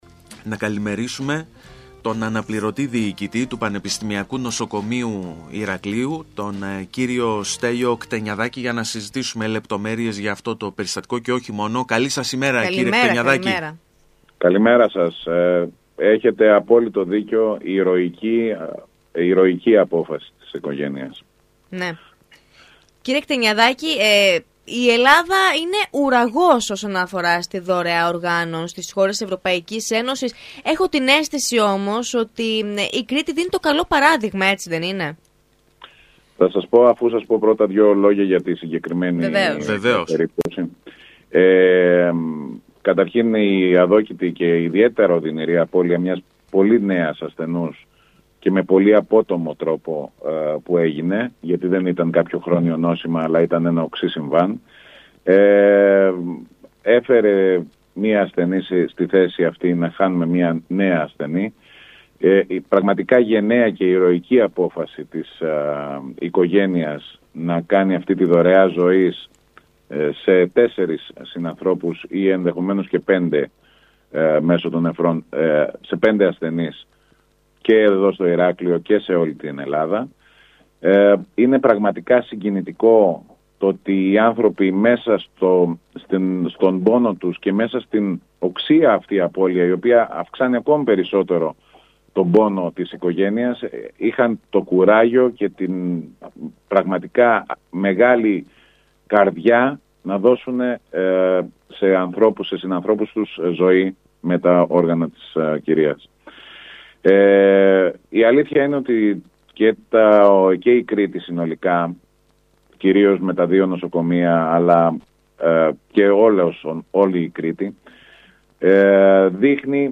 Για μια «ηρωική απόφαση» της οικογένειας έκανε λόγο μιλώντας στον ΣΚΑΪ Κρήτης 92,1